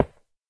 1.21.4 / assets / minecraft / sounds / dig / stone2.ogg
stone2.ogg